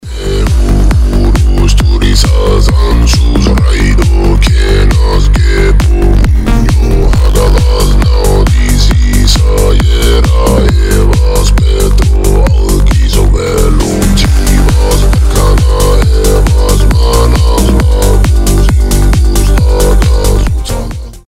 Горловое пение и скандинавское техно